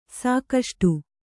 ♪ sākaṣṭu